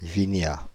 Vignats (French pronunciation: [viɲa]
Fr-Vignats.ogg.mp3